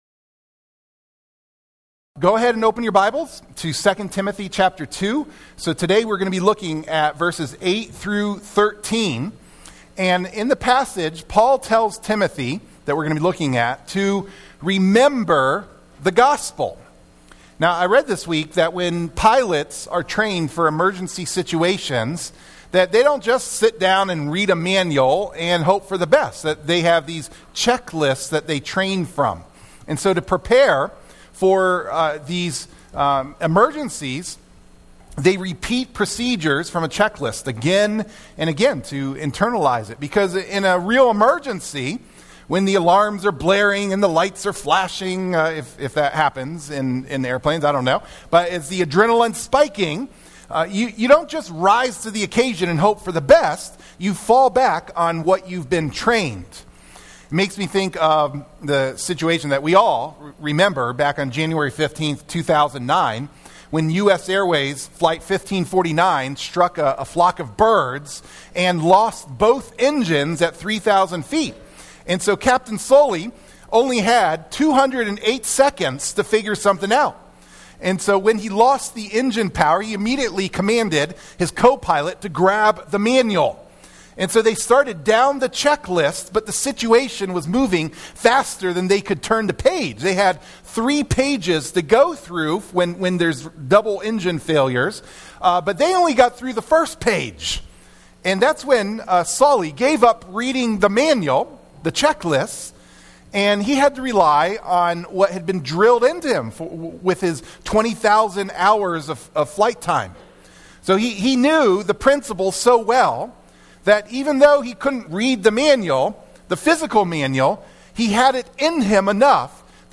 Sermon-2-1-26-MP3-for-Audio-Podcasting.mp3